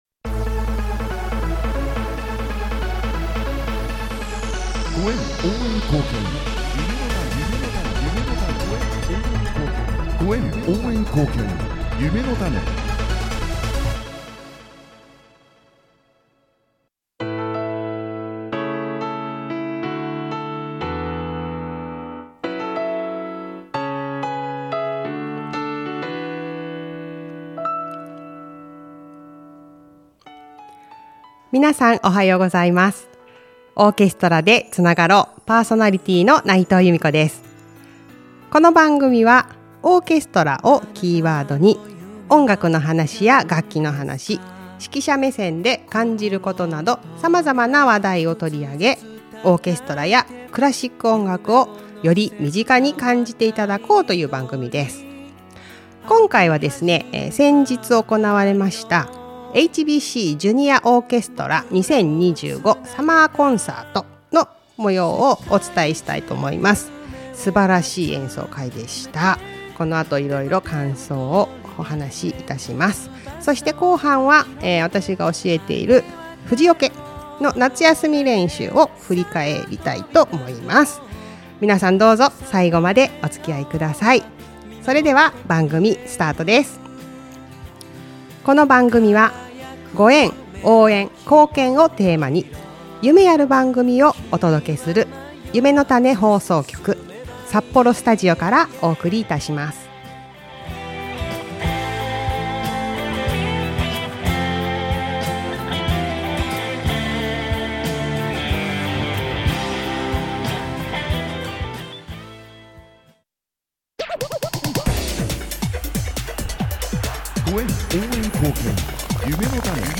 今回は、HBCジュニアオーケストラ2025サマーコンサートの模様をお伝えしました❣